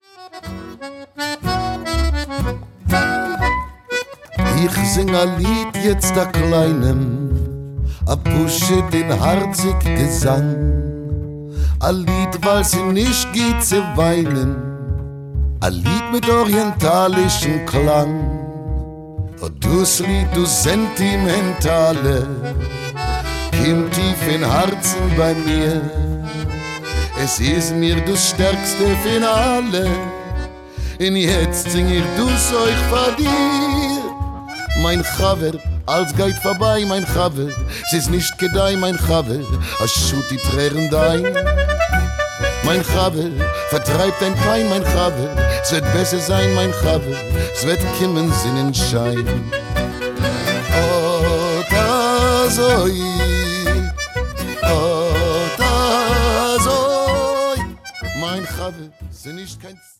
Tango oyf Yiddish (Vol. 2)